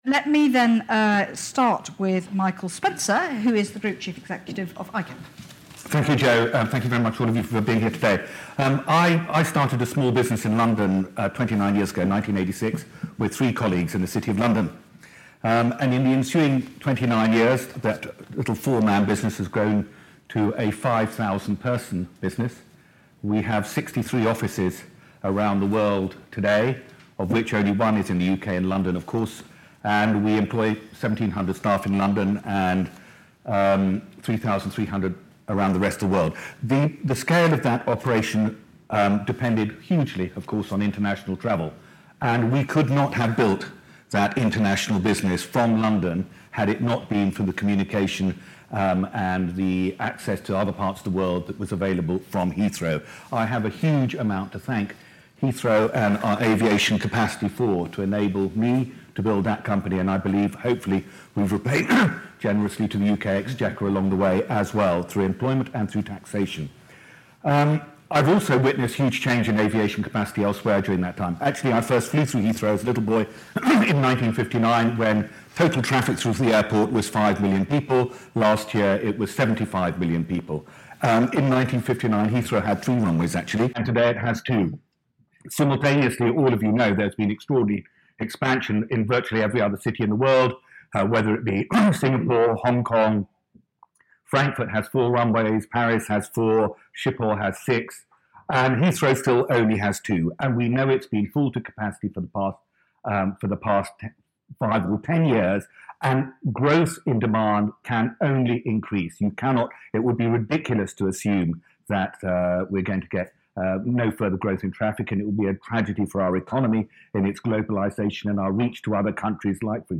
Baroness Jo Valentine, chief executive of London First, introduces three leaders of major British businesses, who explain why they are backing a new runway at Heathrow.
Speaking at the Let Britain Fly event